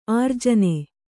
♪ ārjane